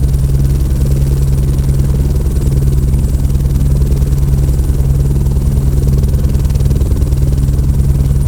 rotor_interior.wav